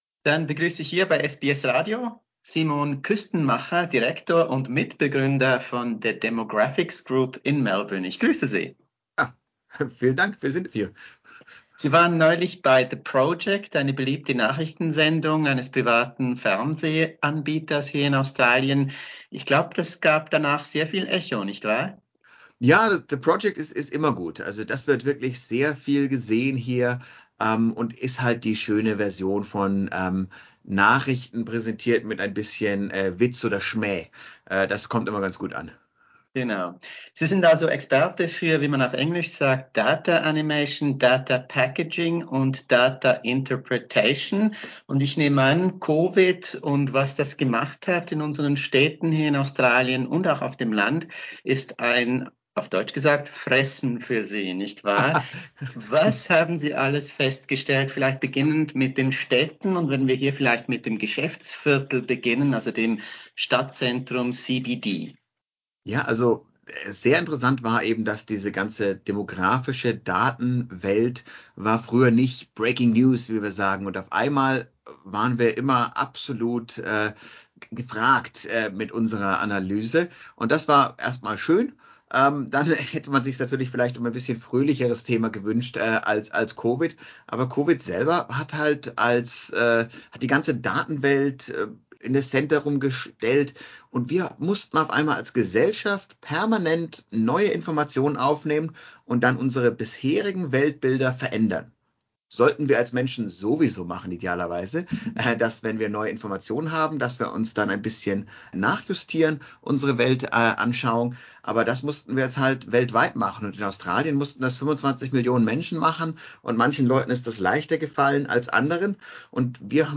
Hear more about this, and how COVID has changed our Australian cities, in a fascinating conversation.